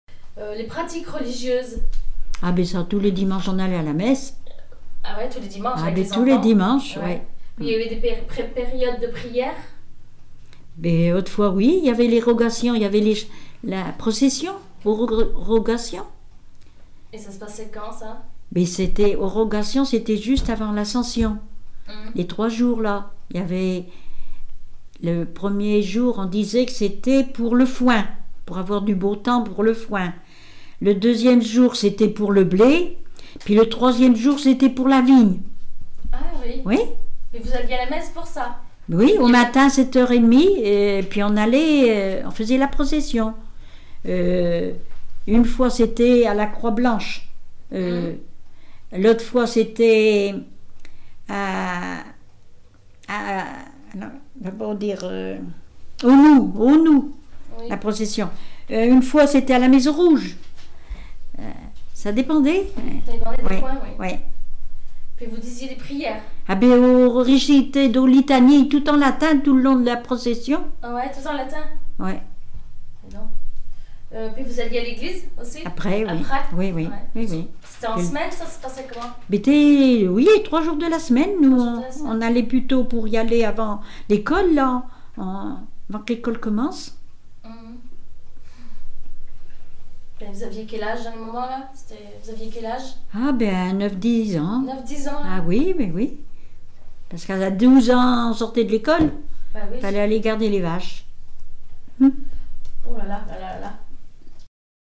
Témoignages sur la vie domestique
Catégorie Témoignage